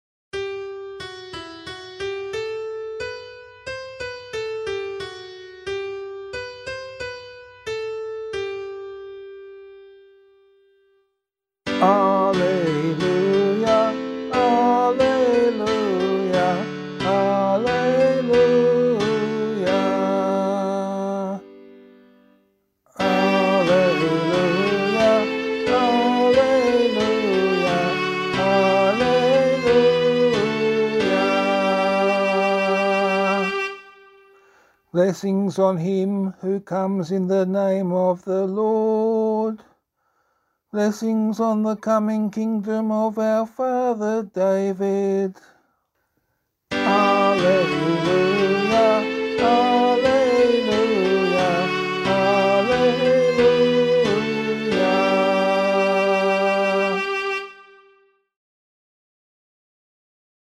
Gospel Acclamation for Australian Catholic liturgy.
keyboard • lead sheet • cantor • tablet • assembly • slide • vocal